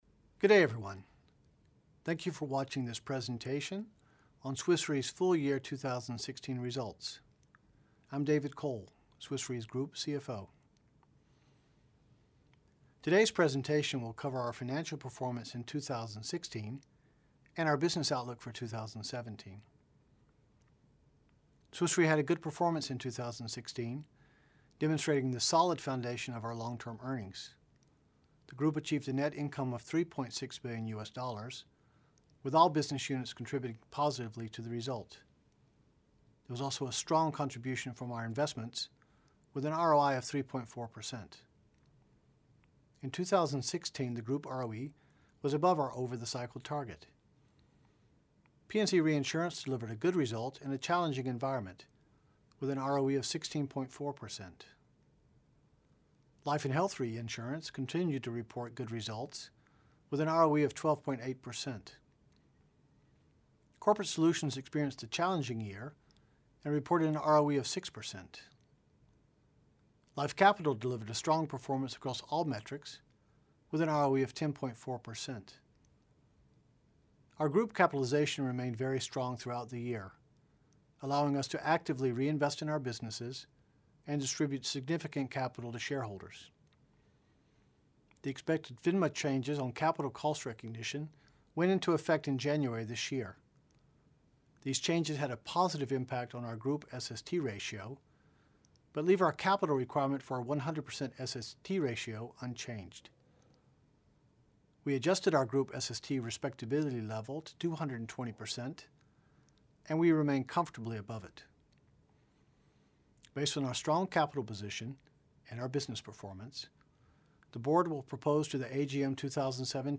Audio of Annual Results 2016 Video Presentation